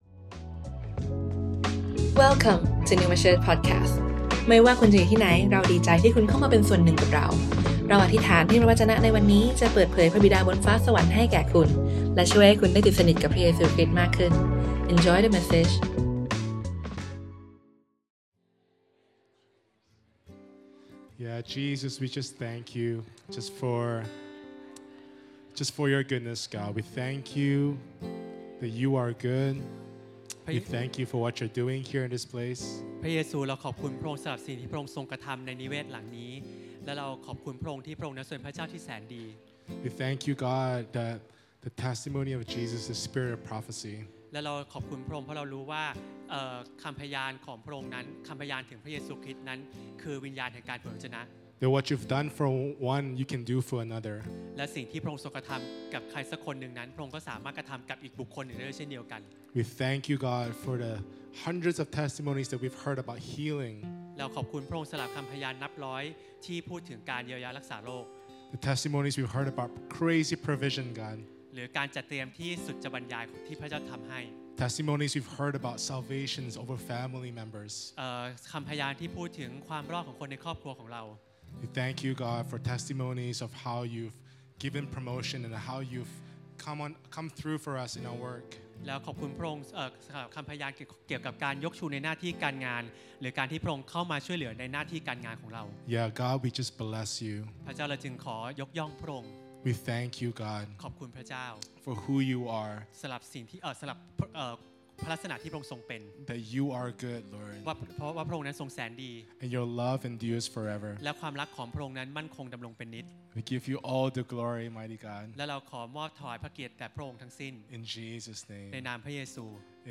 Originally recorded on Sunday 21st July 2024, at Neuma Bangkok.